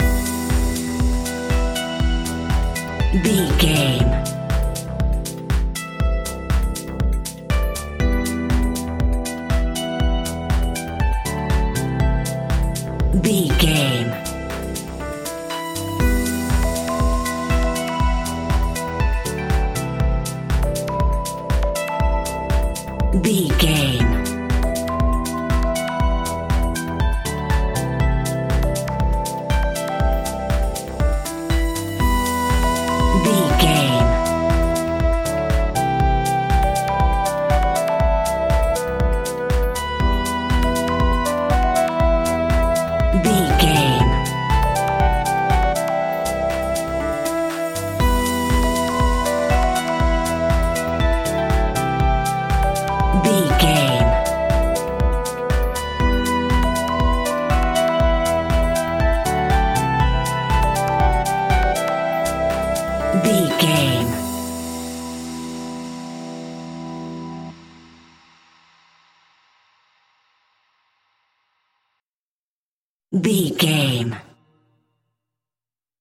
Aeolian/Minor
uplifting
driving
energetic
bouncy
synthesiser
drum machine
electro house
progressive house
synth leads
synth bass